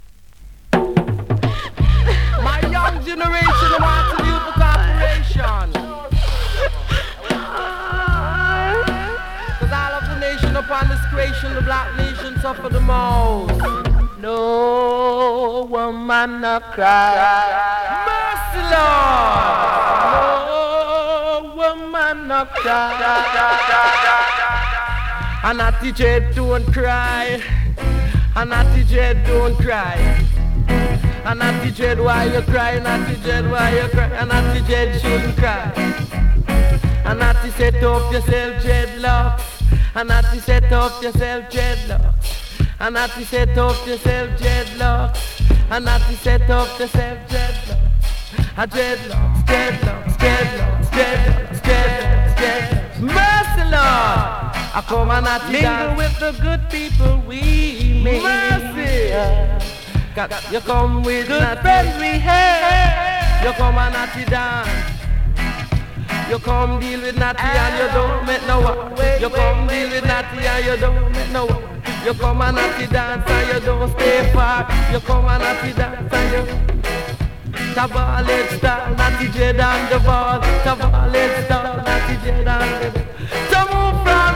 NEW IN!SKA〜REGGAE
スリキズ、ノイズかなり少なめの